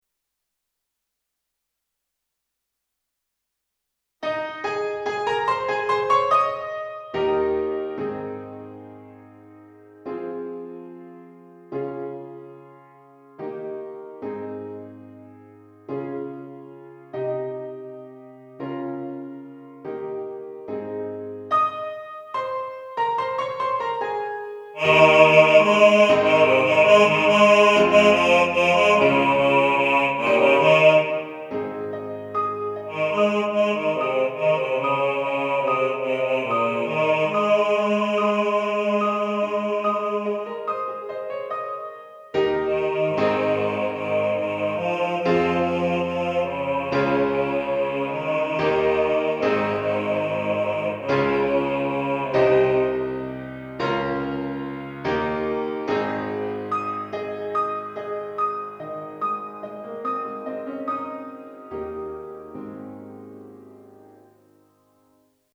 "Simple Gifts Bass".
Simple-Gifts-Bass.mp3